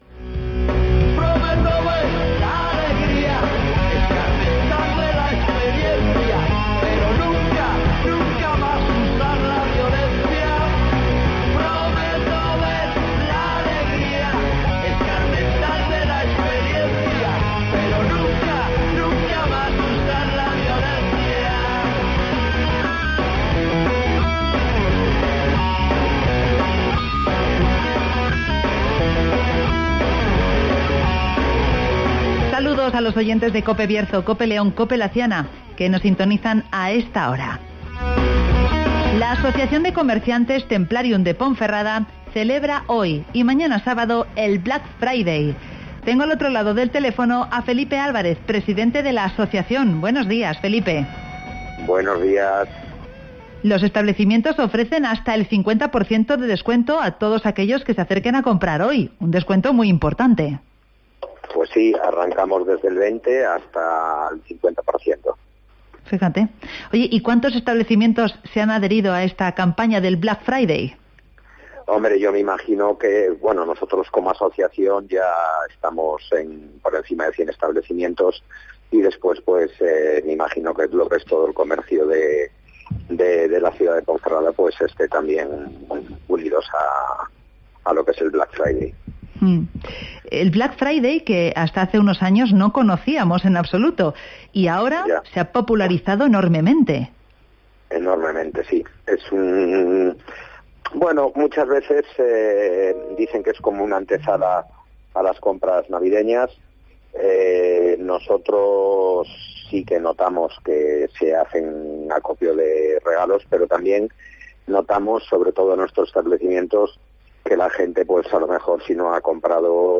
Black Friday en Templarium (Entrevista